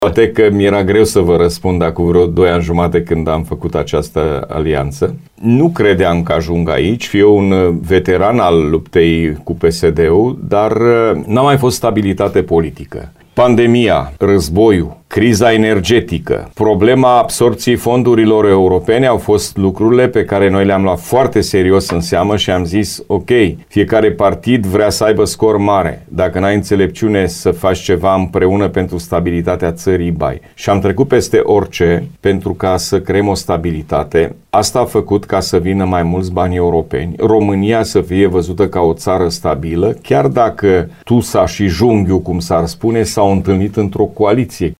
El a declarat postului nostru că membrii principalelor 2 partide trebuie să înțeleagă faptul că numai o colaborare poate asigura stabilitatea politică în aceste vremuri tulburi.